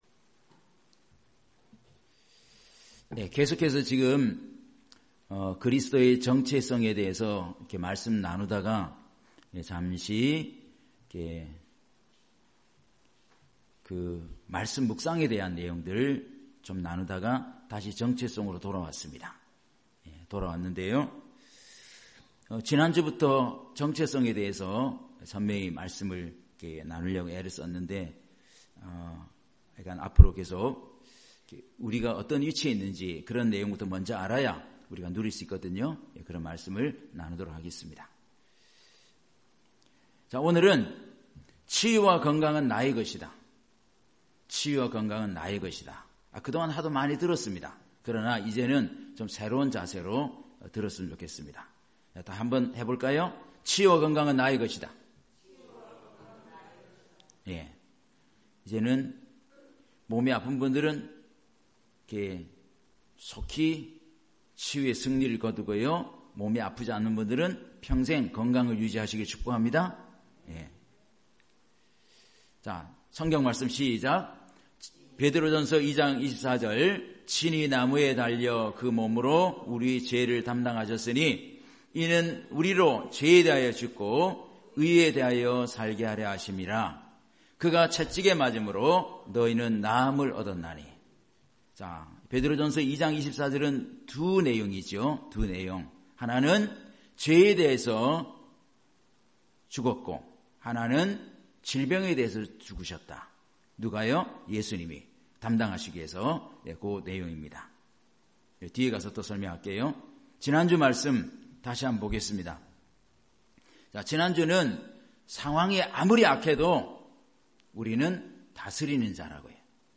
주일 오전 말씀 - 치유와 건강은 내 것이다